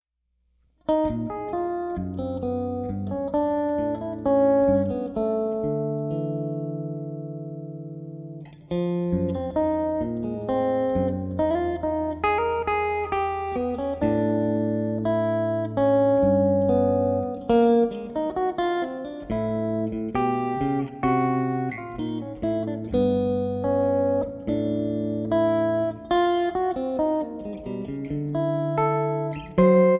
Guitar
Harmonica